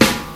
• '00s Hip-Hop Steel Snare Drum Sample D# Key 31.wav
Royality free snare drum sound tuned to the D# note. Loudest frequency: 2232Hz
00s-hip-hop-steel-snare-drum-sample-d-sharp-key-31-LLD.wav